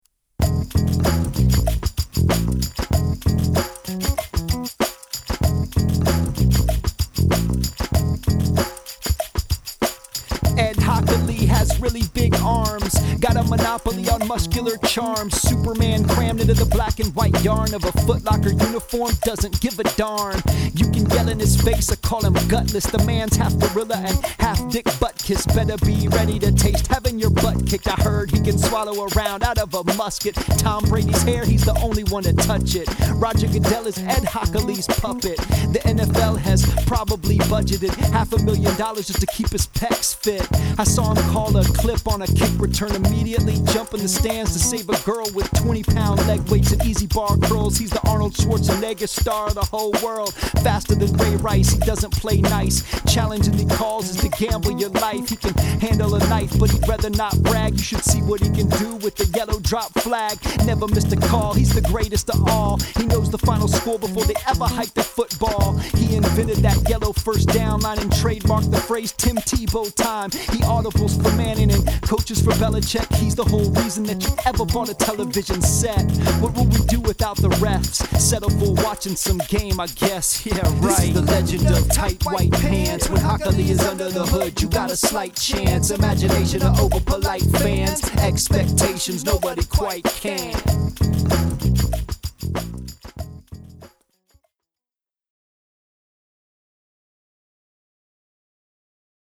Today’s song blog here: